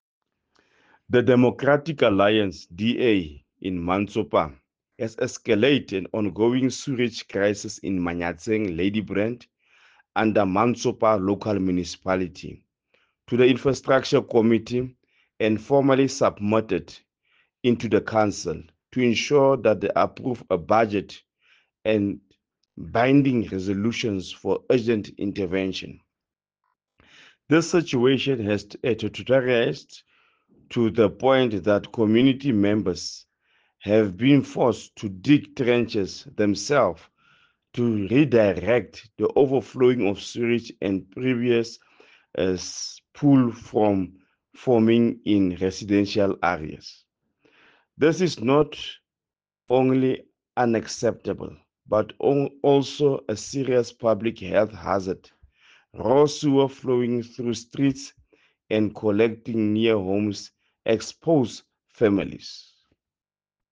Sesotho soundbites by Cllr Nicky van Wyk.